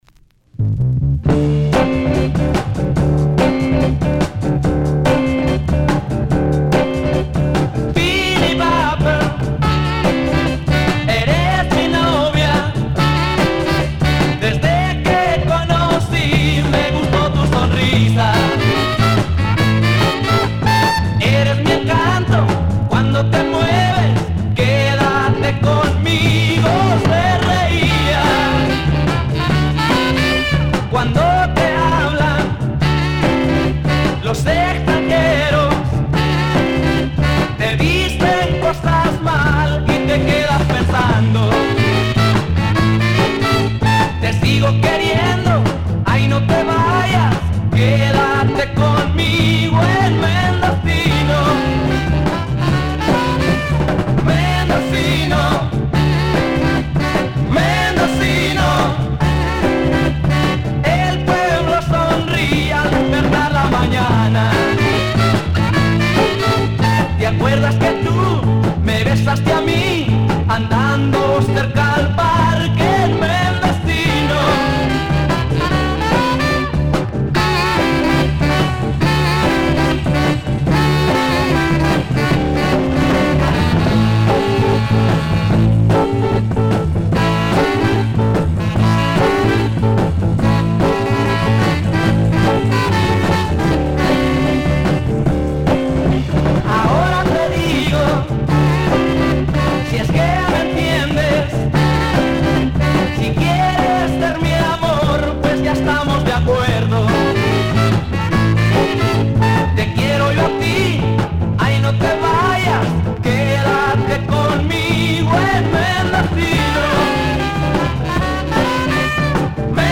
Day 23: Los Covers
Published February 23, 2010 Garage/Rock Comments